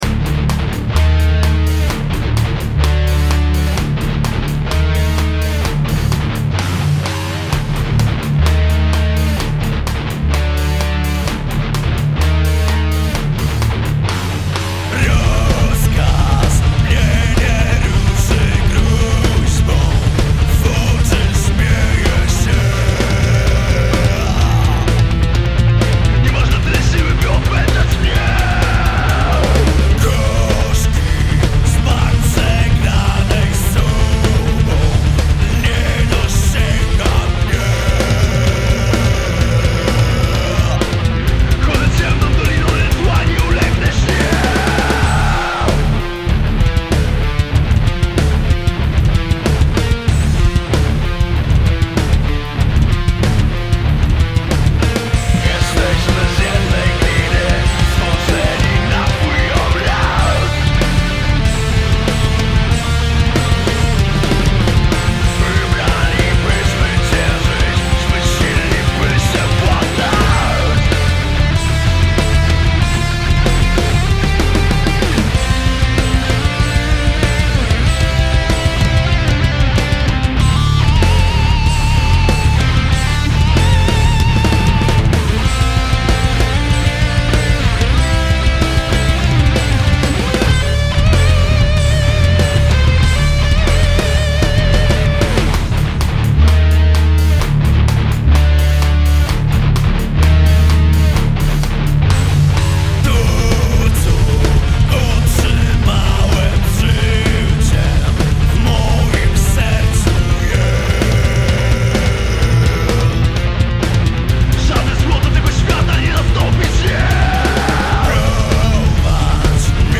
Gatunek: Metal
Gitara Basowa